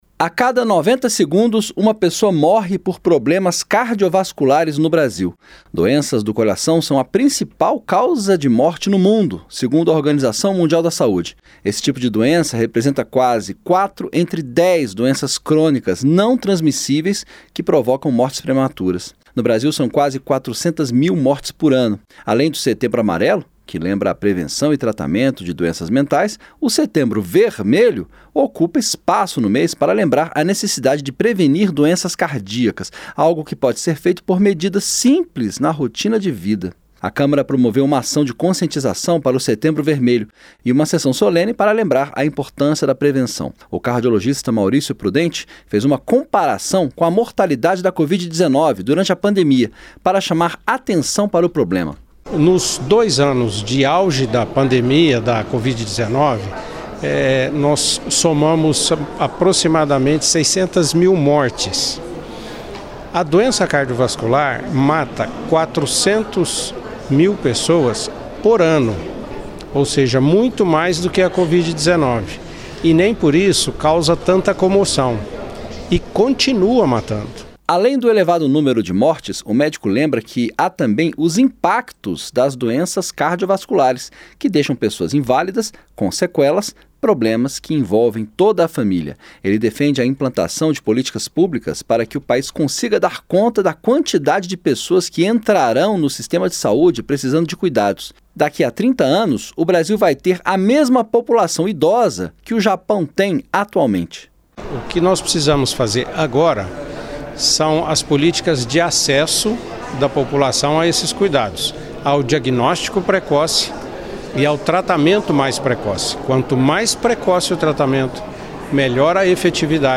O deputado Zacharias Calil (União-GO), que propôs a sessão solene, lembrou que a prevenção é o melhor caminho, e que isso precisa ser feito desde a infância.